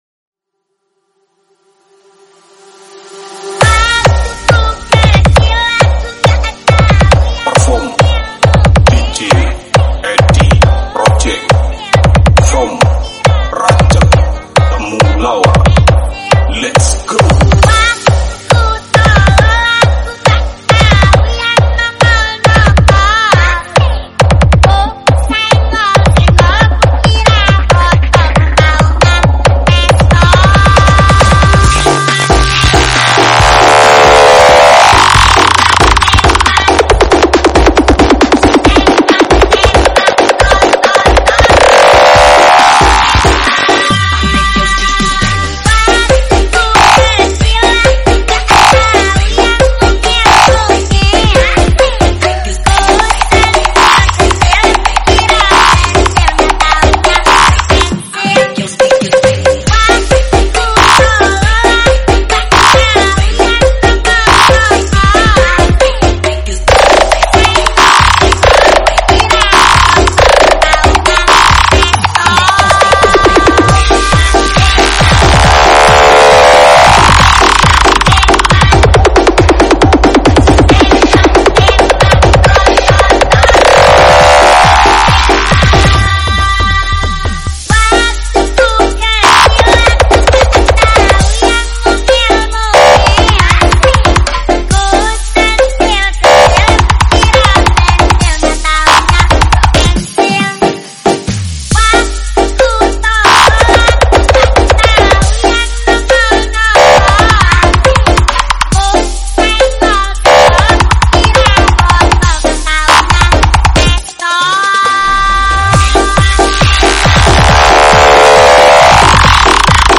nrotok bass